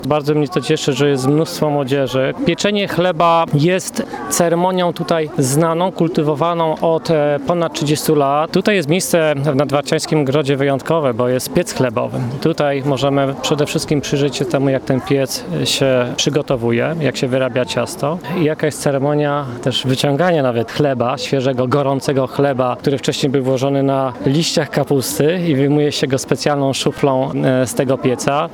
mówi Jacek Olczyk, wójt gminy Pątnów.